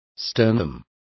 Complete with pronunciation of the translation of sternum.